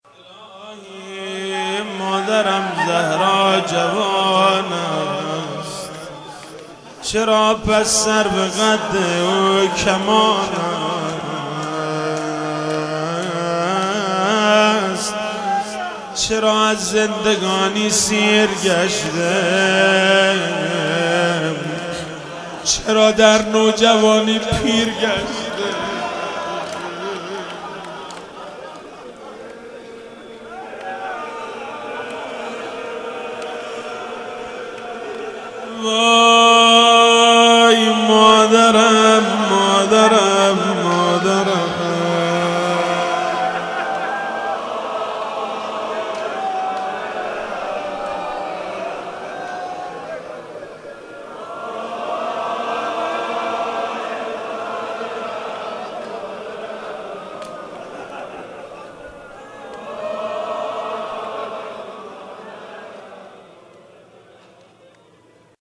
فاطمیه